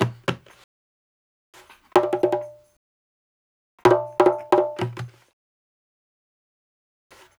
128-BONGO3.wav